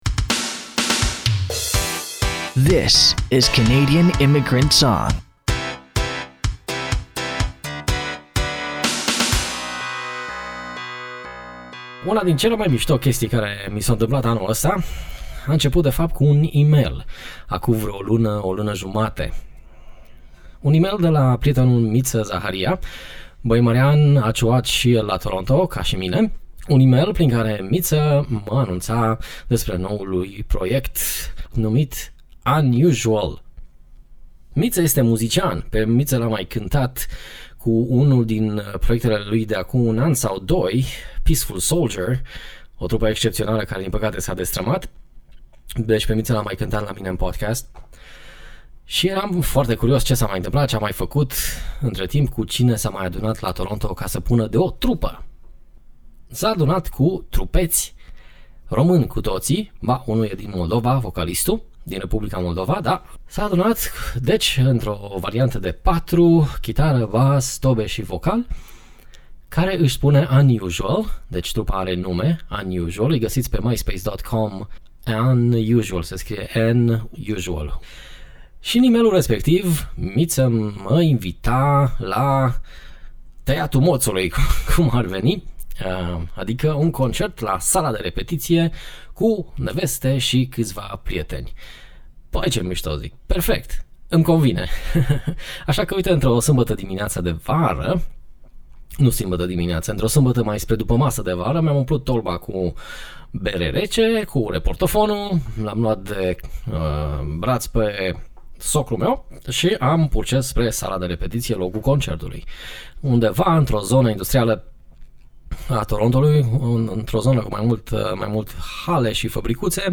Un concert la sala de repetitii.
bootleg vara 2010
live la sala de repetitii